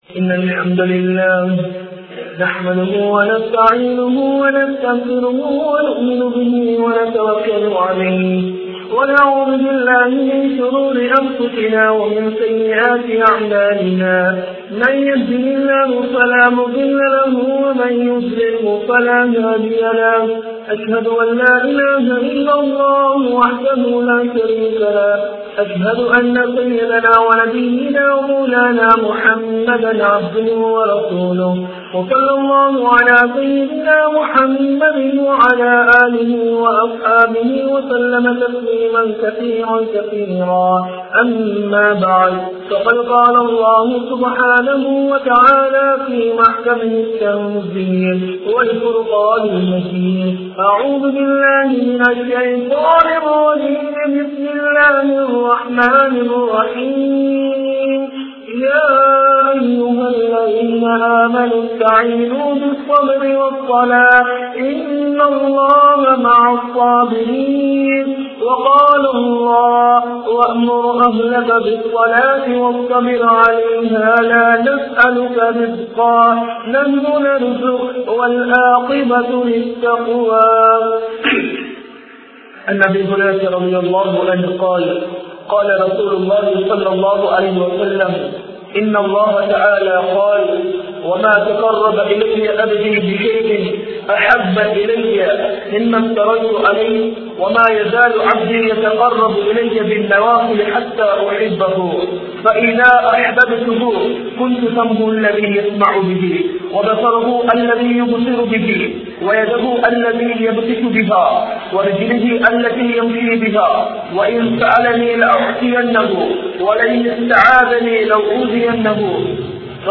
Sunnathaana Tholukaiyin Mukkiyathuvam(சுன்னத்தான தொழுகையின் முக்கியத்துவம்) | Audio Bayans | All Ceylon Muslim Youth Community | Addalaichenai
Mt Lavenia Jumua Masjth